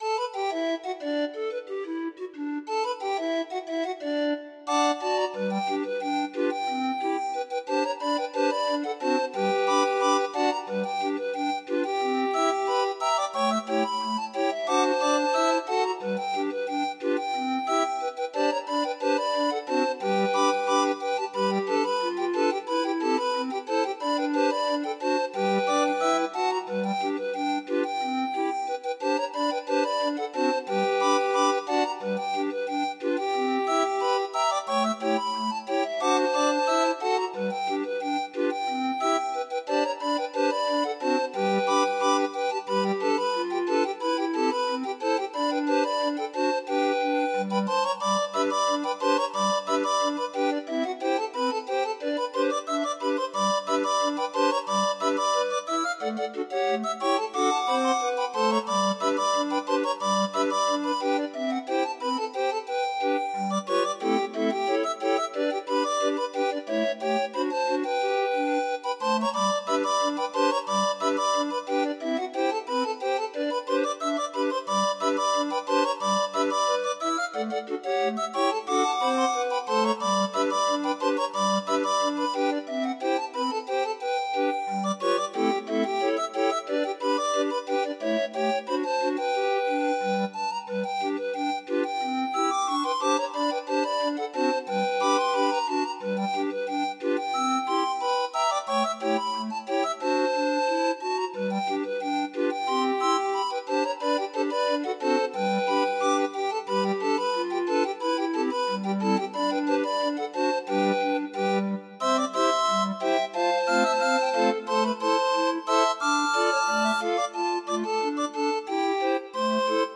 Demo of 26 note MIDI file